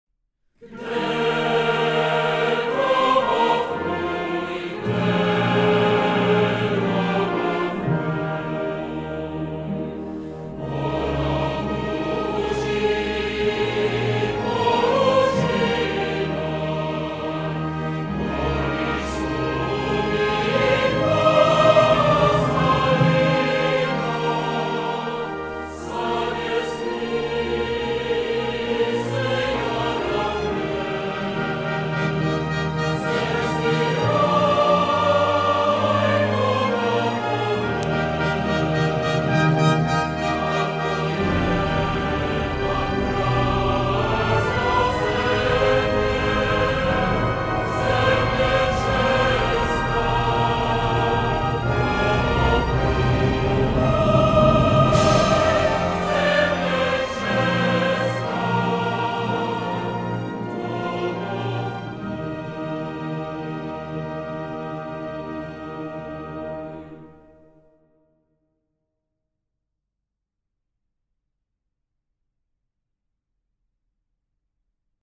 Sbor Národního divadla - Kde domov můj?
Zvukový záznam nové nahrávky české státní hymny v podání Sboru Národního divadla